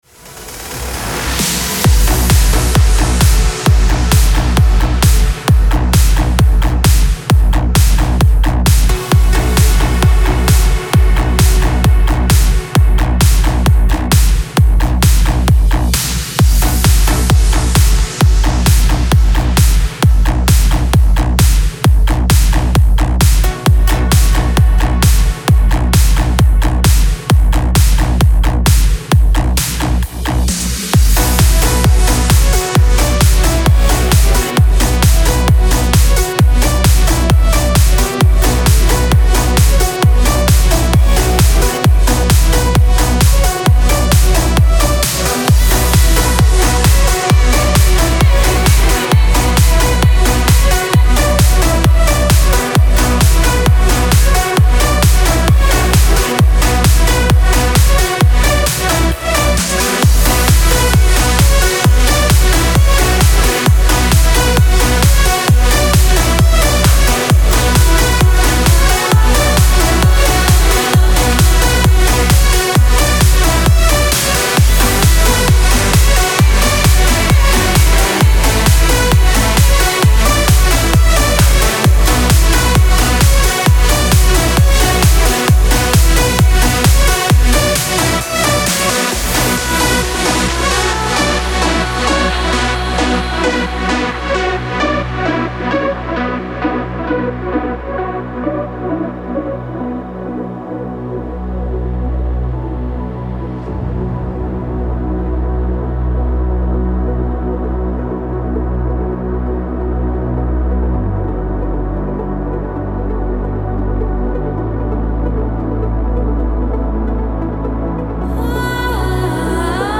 Trance retro